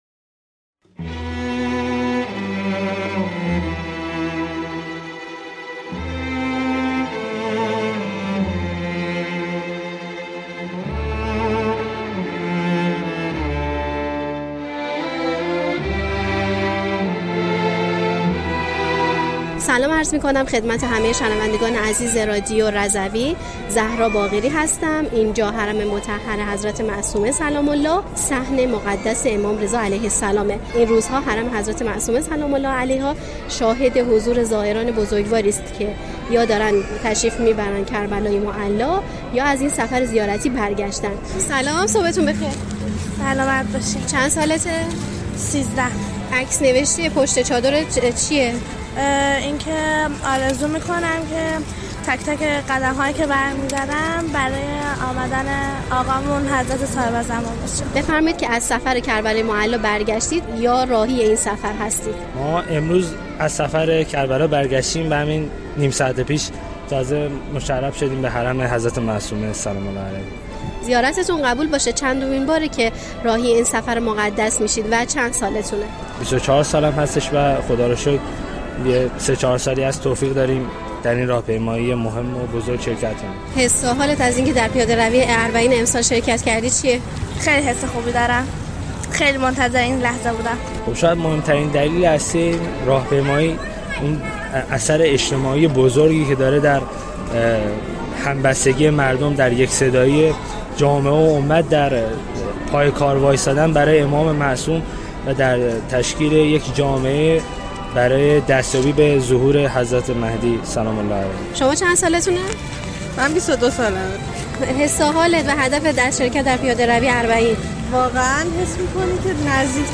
در گزارش خبرنگار رادیو رضوی در صحن و سرای امام رئوف در ایام اربعین حسینی، مردم از زیارت‌های نیابتی به نام شهدا می‌گویند. از دعاهایی که زوار برای جاماندگان داشته‌اند و از شهدایی نام بردند که در مشایه یاد و خاطره آنها را همرا خود کردند.